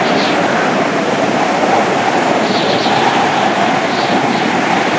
rr3_sfx_drafting_loop.wav